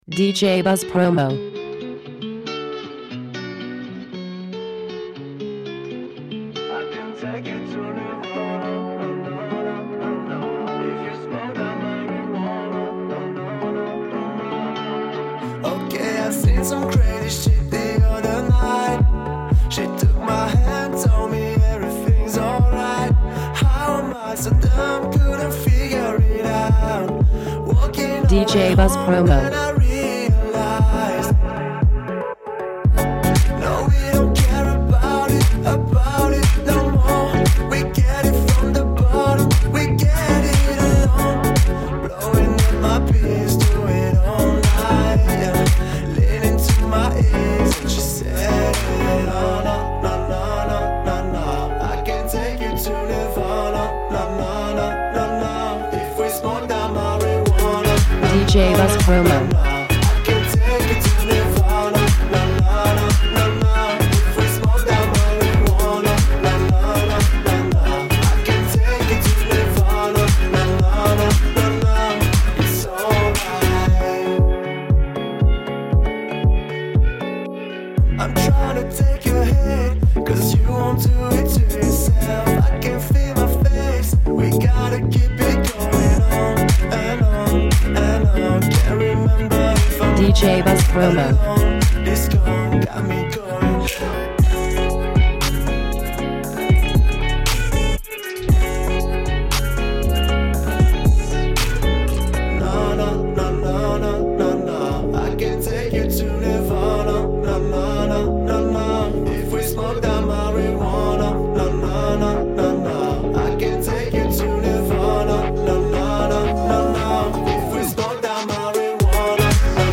influenced by future beat, RnB and pop
Original Mix
is a House music track mixing latin touch and Trap breaks.